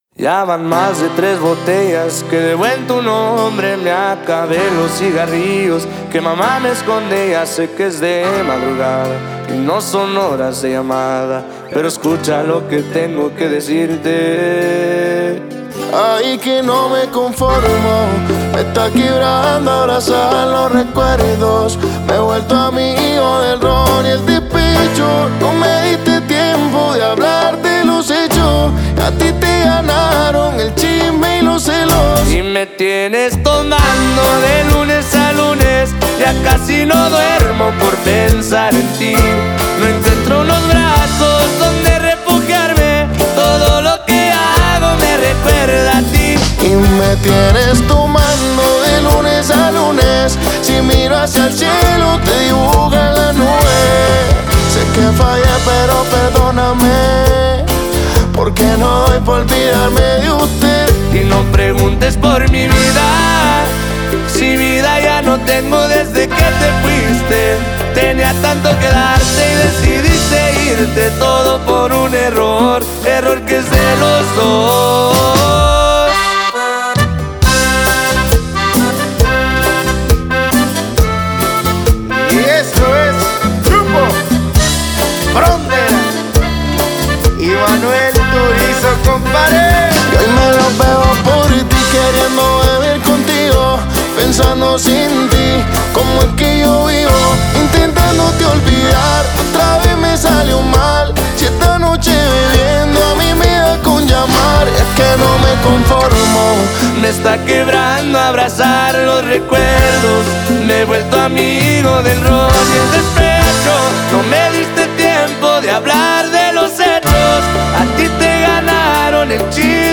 Regional Mexicano